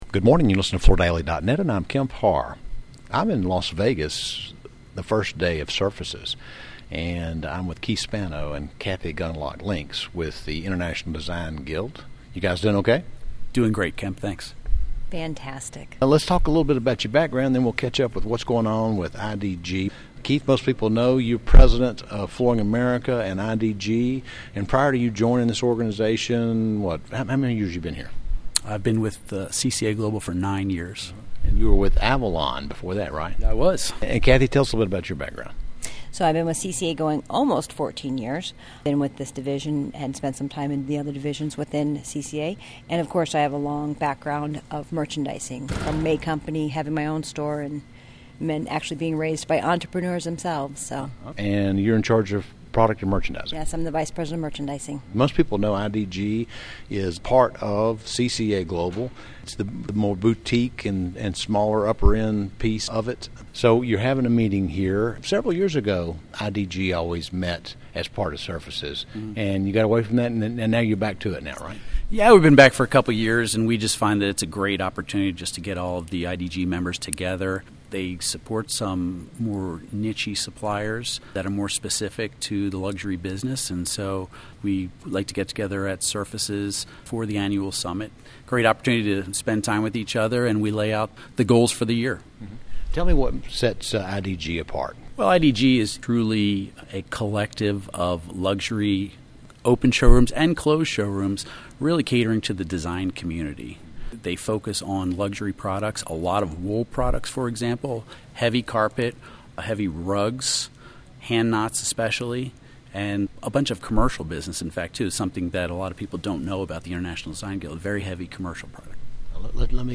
Listen to the interview, recorded at Surfaces in Las Vegas, to hear more about their business, their focus, and product mix.